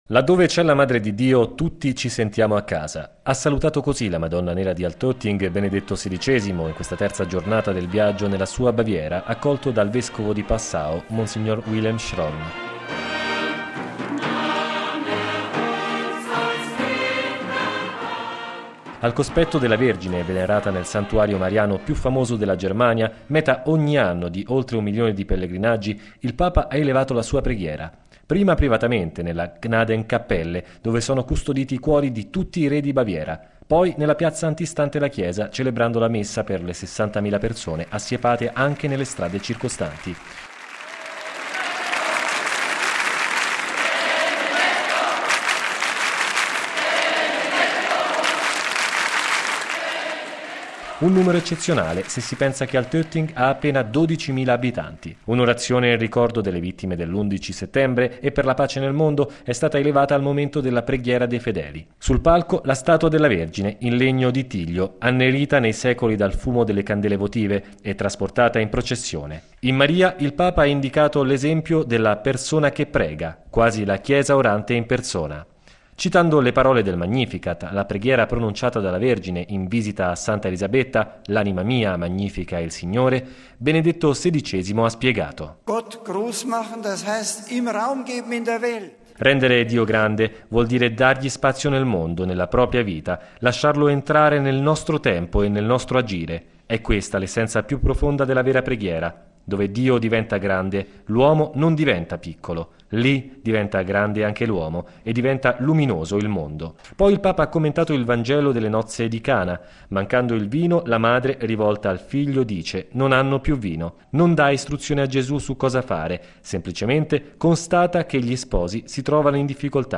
(11 settembre 2006 - RV) Il Papa stamane ha presieduto la Messa nel santuario di Altötting, il cuore mariano della Baviera. Ha esortato i fedeli a imparare da Maria a pregare nel modo giusto: la Vergine alle nozze di Cana non chiede a Gesù di compiere un miracolo, ma affida a Lui le sue preoccupazioni.